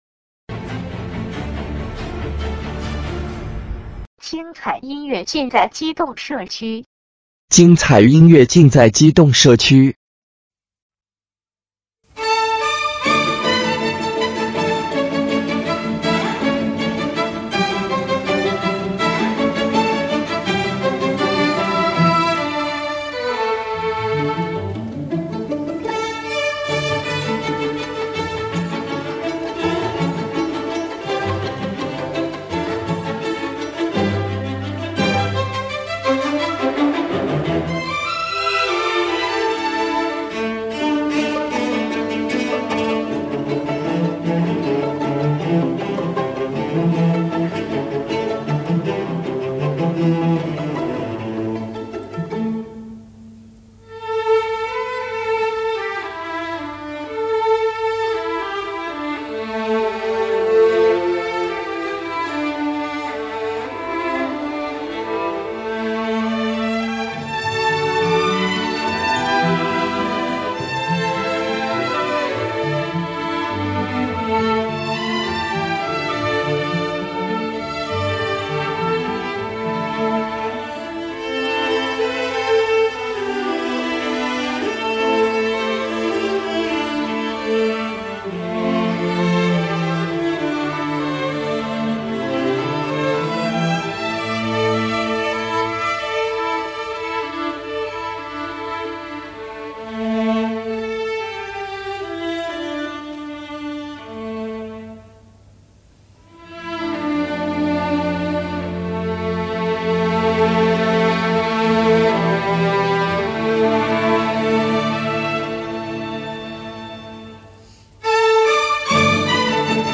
云南民歌
陕西民歌